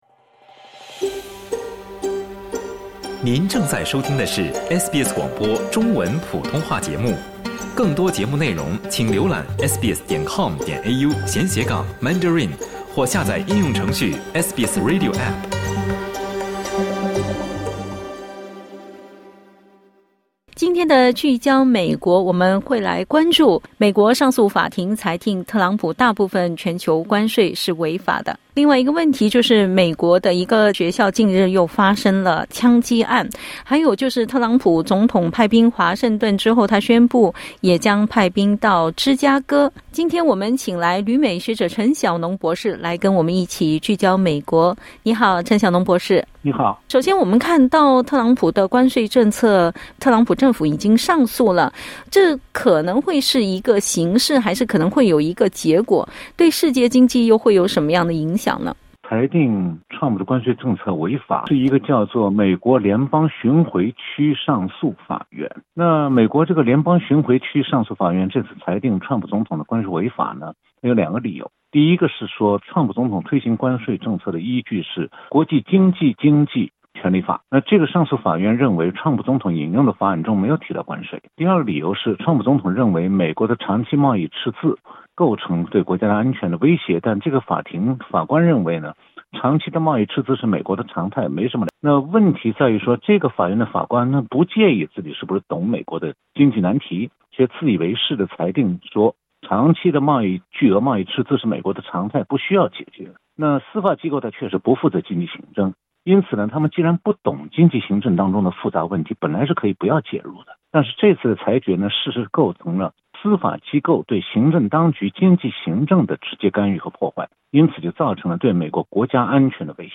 (点击音频收听详细采访） 美国贸易代表格里尔（Jamieson Greer）近日表示，特朗普(Donald Trump）政府仍在与各贸易伙伴推进谈判。